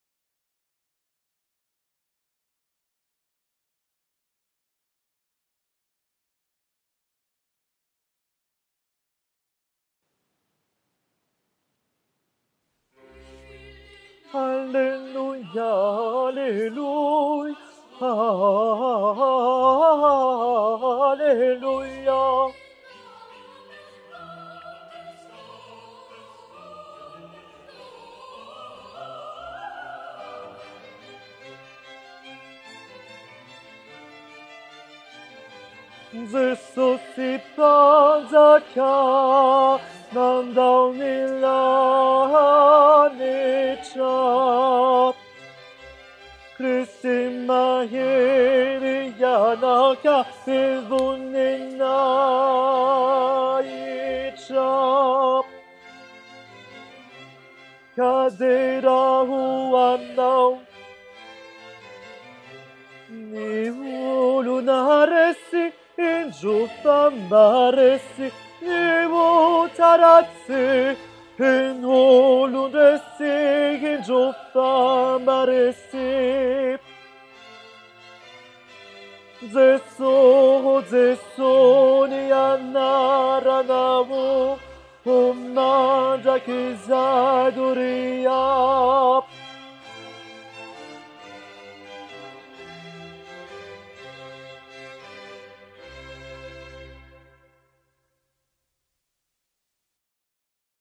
cantate_142_tenor-23_04_2014-21-37.mp3